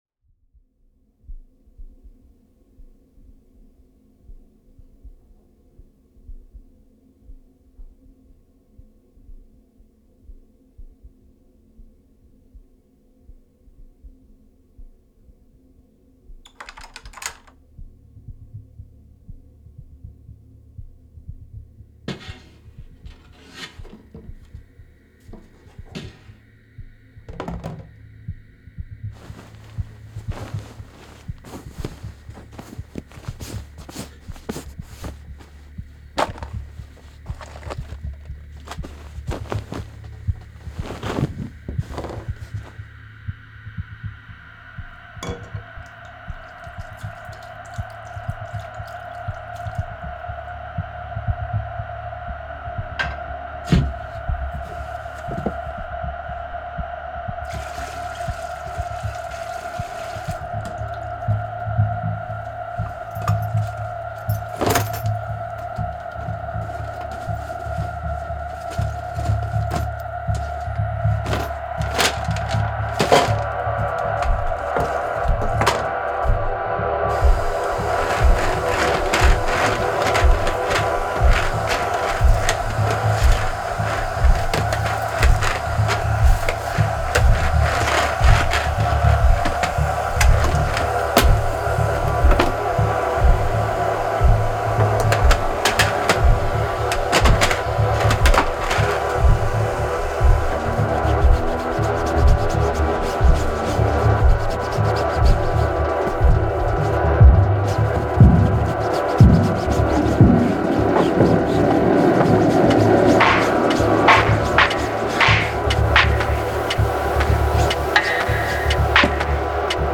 He mixes electronic and organic instruments to craft unique musical universes.
J’ai également laissé la track de sound design dedans pour le rapport entre les sons et la musique.